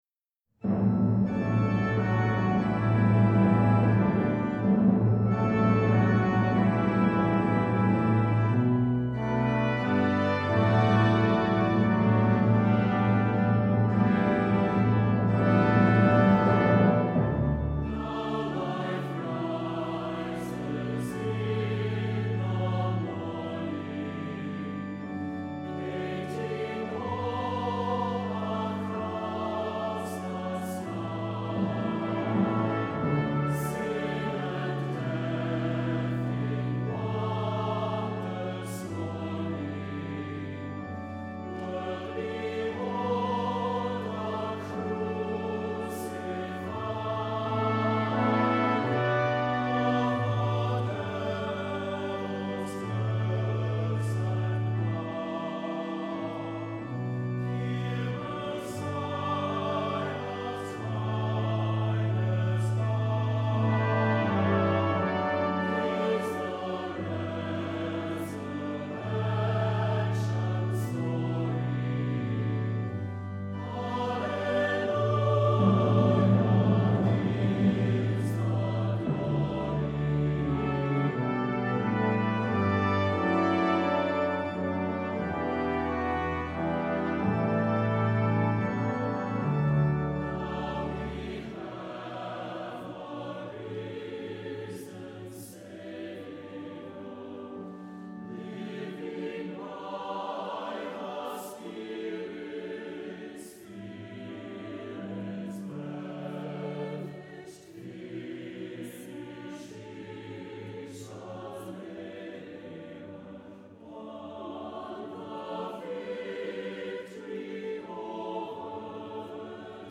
Voicing: SATB, Optional Congregation